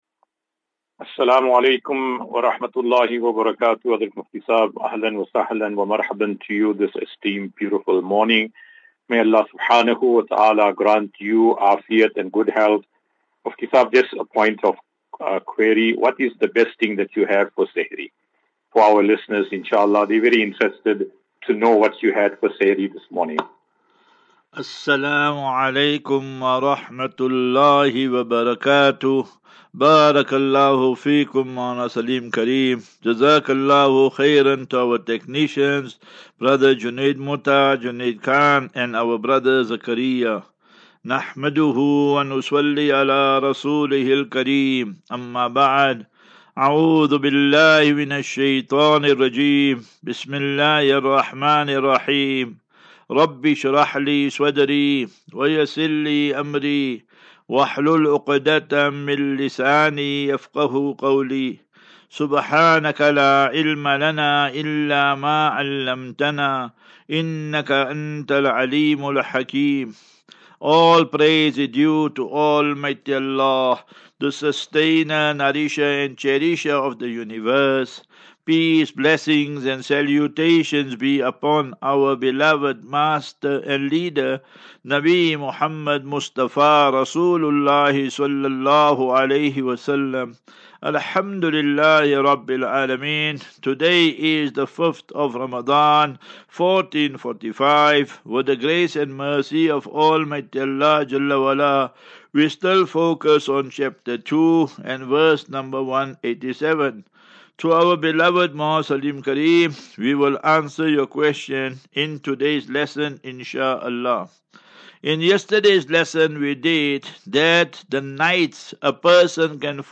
As Safinatu Ilal Jannah Naseeha and Q and A 16 Mar 16 March 2024.